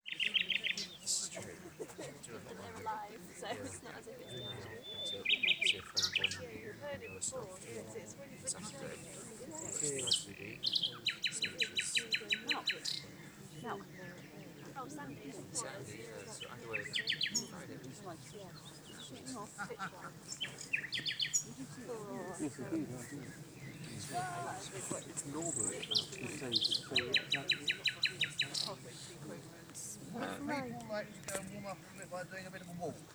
A Nightingale Walk
There is a certain magical tone to the voice of a nightingale, that can only be truly known when directly experienced. The joyful variety of its song (although some would say melancholic), of overwhelming amplitude given its small size, dominates the audiosphere of those places fortunate enough to play host to this melodious passerine.
It was a strangely still and cool evening and as the light dimmed the nightingales seemed to come into their own.